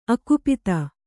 ♪ akupita